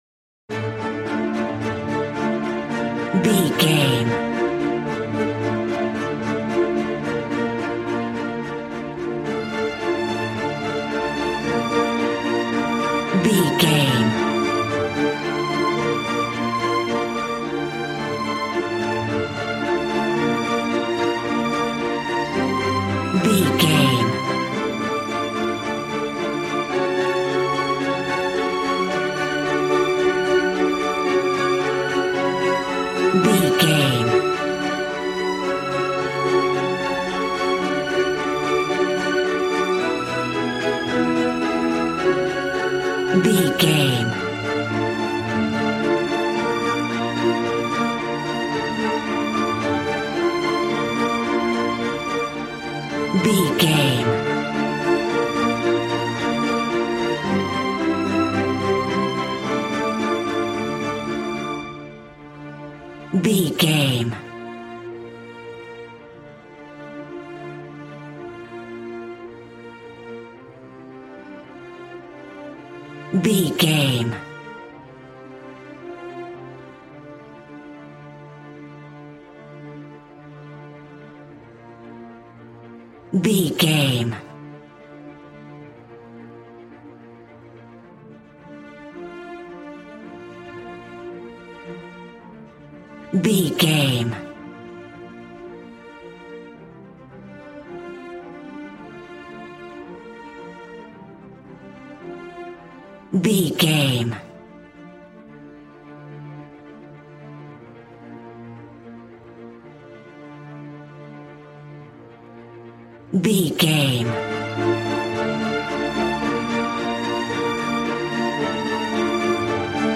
Aeolian/Minor
D
regal
brass